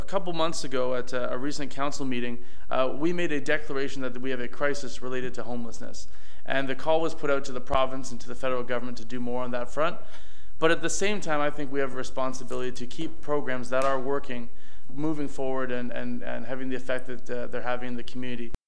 Belleville City Council meets, December 11, 2023.
Councillor Tyler Allsopp calls it an incredibly important program for the downtown core.